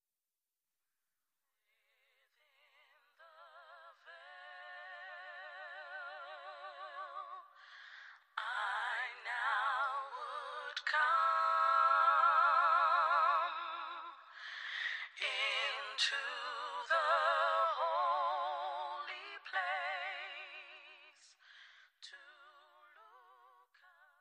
• Sachgebiet: Black Gospel